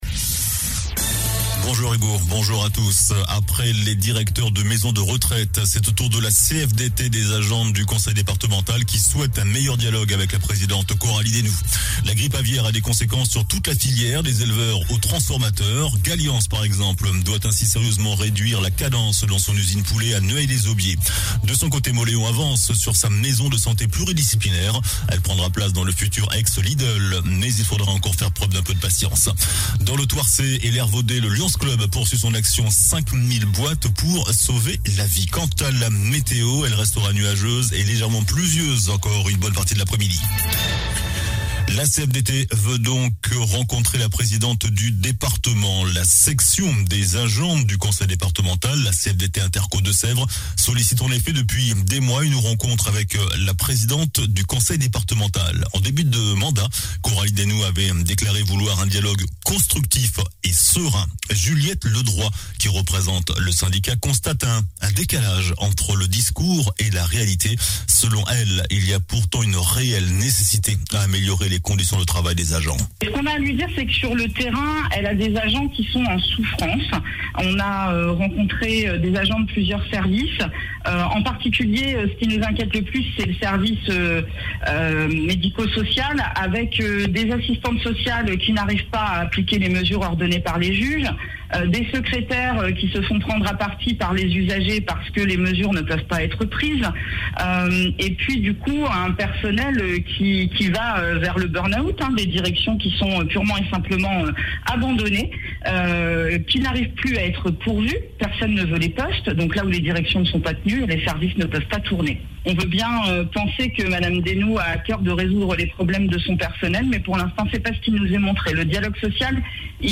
JOURNAL DU MARDI 05 AVRIL ( MIDI )